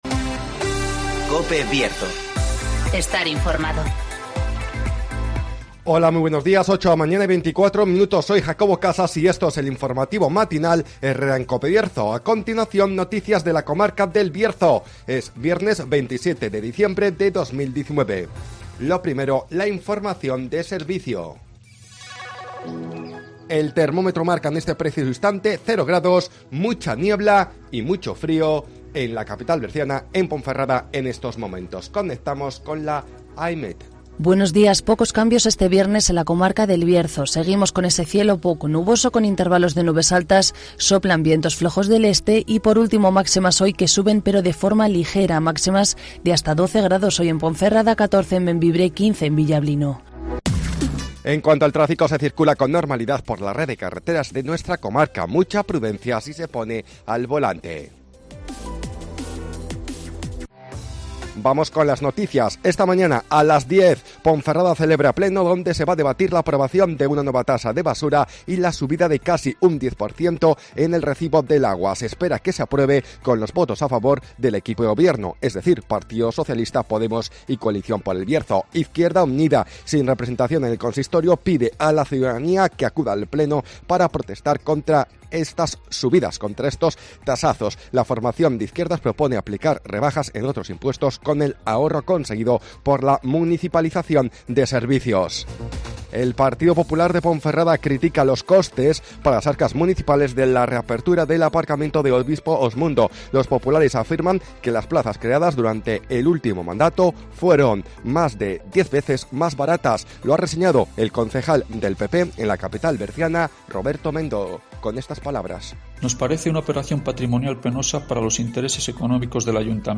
INFORMATIVOS BIERZO
Conocemos las noticias de las últimas horas de nuestra comarca, con las voces de los protagonistas